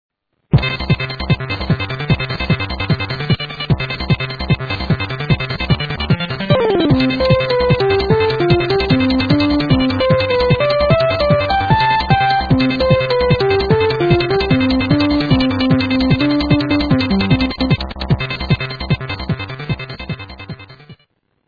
ループ失敗。